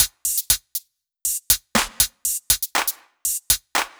Index of /musicradar/french-house-chillout-samples/120bpm/Beats
FHC_BeatA_120-03_HatClap.wav